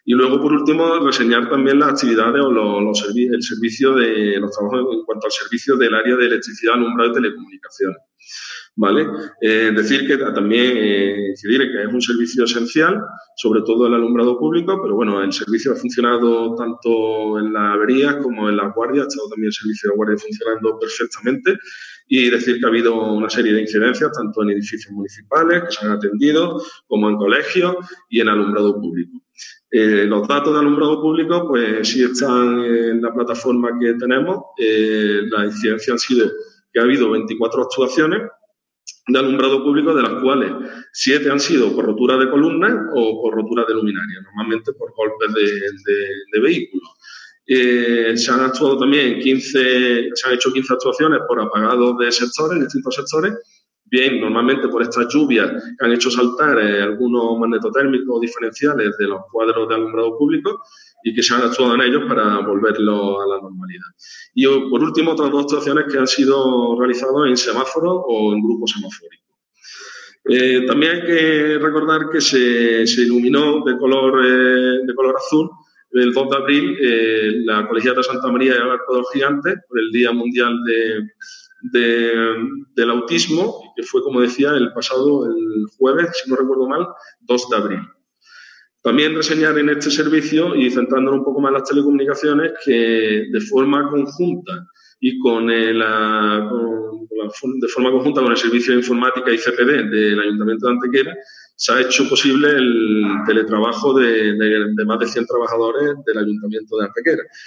El alcalde de Antequera, Manolo Barón, y el concejal Juan Álvarez han desarrollado en la mañana de hoy una rueda de prensa telemática para la valoración del trabajo que se lleva realizando durante el Estado de Alarma en las áreas municipales de Anejos, Medio Ambiente y Electricidad.
Cortes de voz